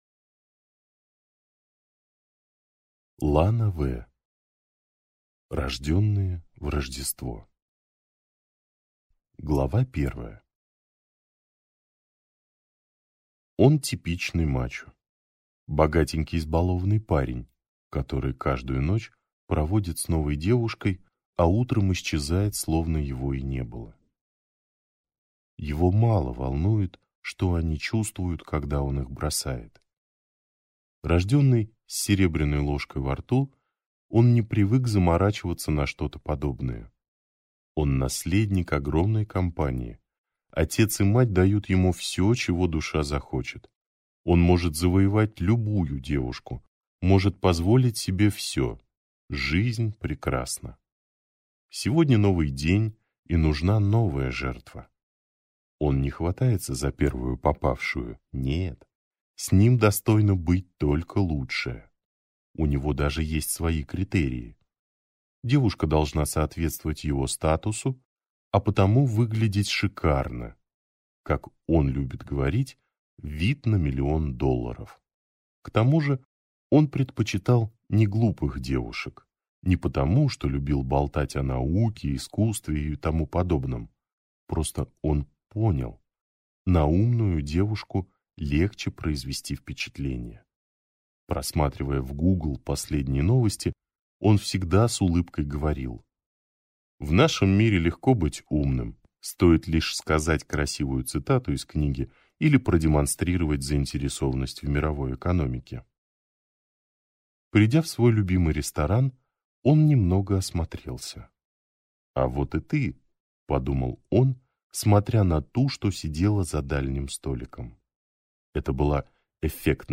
Аудиокнига Рождённые в рождество | Библиотека аудиокниг